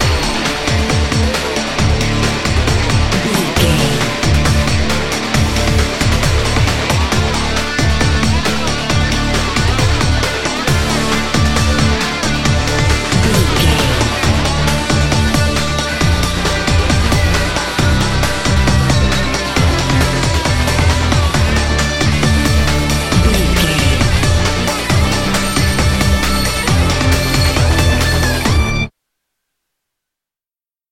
Aeolian/Minor
hard rock
lead guitar
bass
drums
aggressive
energetic
intense
nu metal
alternative metal